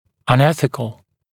[ˌʌn’eθɪkl][ˌан’эсикл]неэтичный, неэтично